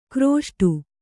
♪ krōṣṭu